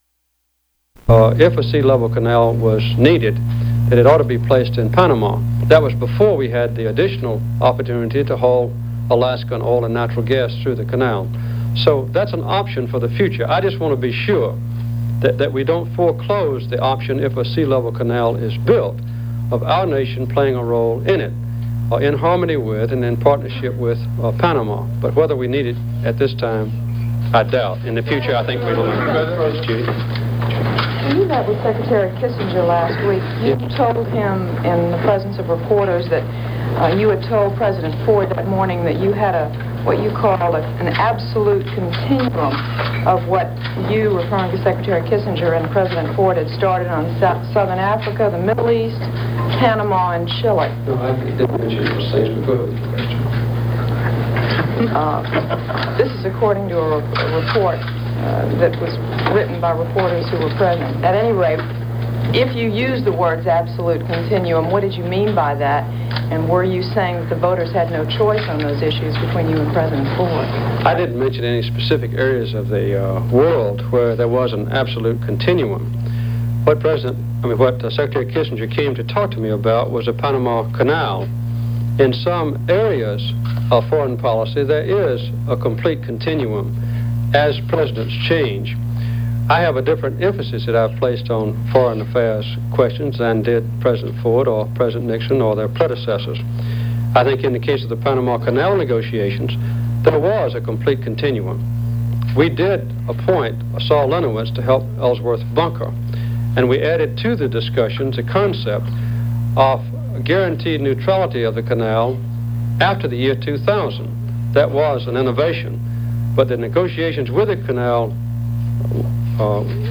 Press conference; 14th; excerpts